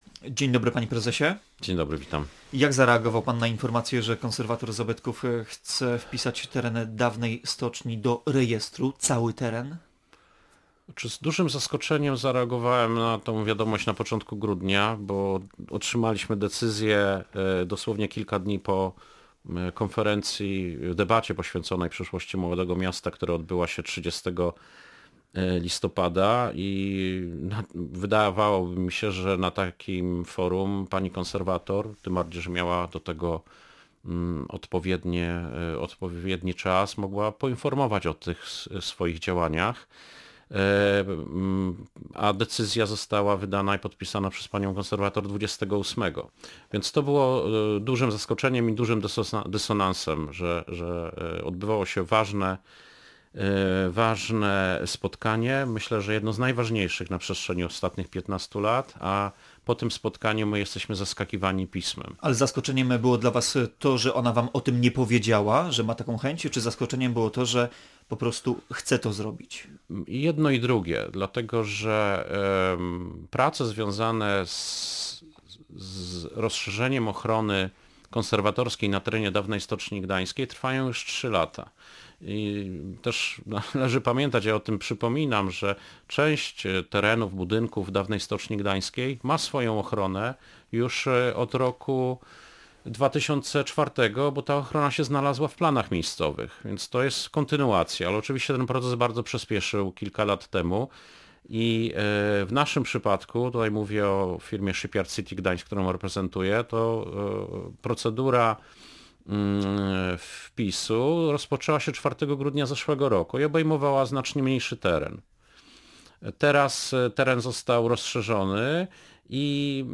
– Nie zgadzam się z tym poglądem.